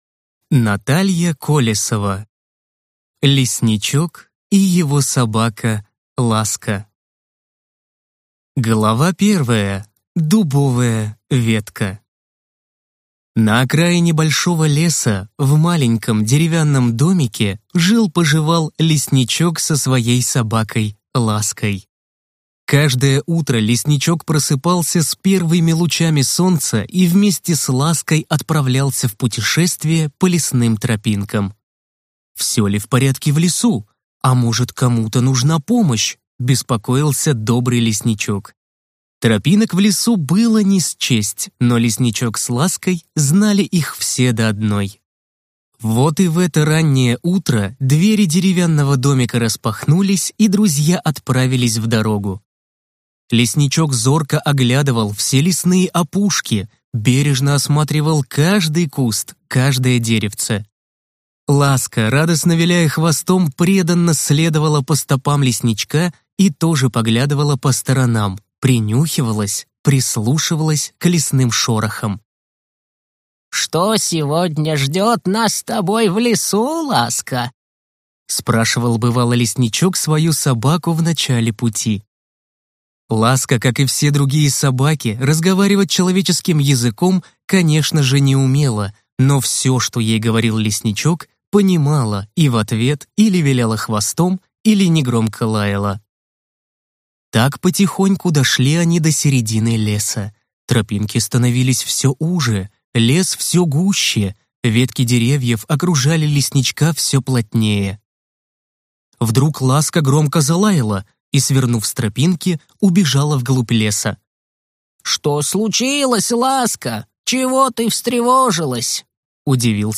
Аудиокнига Лесничок и его собака Ласка | Библиотека аудиокниг
Прослушать и бесплатно скачать фрагмент аудиокниги